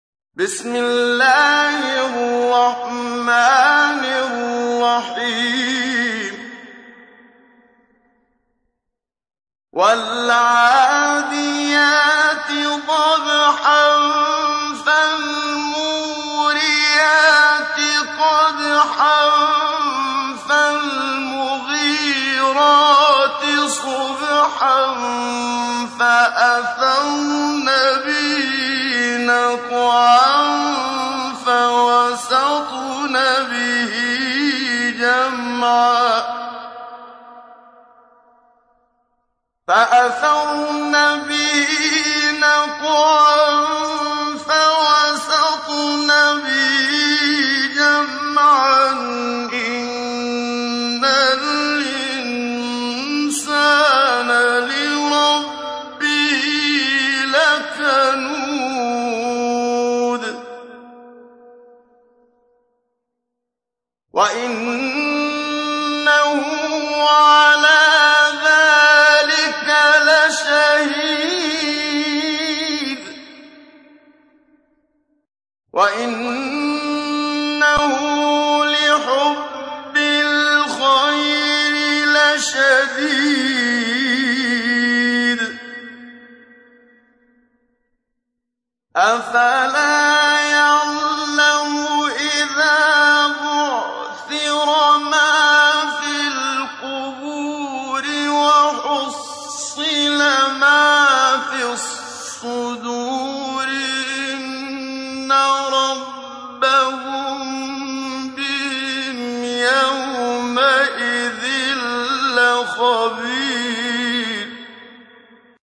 تحميل : 100. سورة العاديات / القارئ محمد صديق المنشاوي / القرآن الكريم / موقع يا حسين